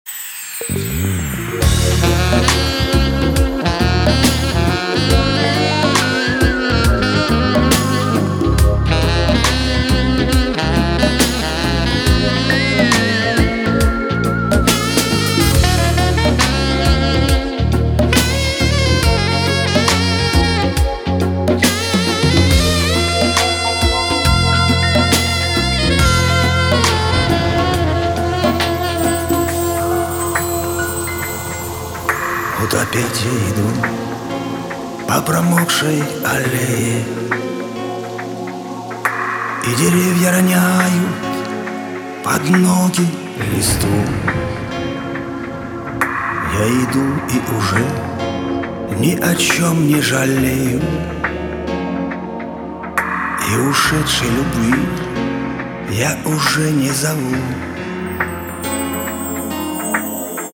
• Качество: 320, Stereo
грустные
спокойные
инструментальные
Саксофон
Красивая игра на саксофоне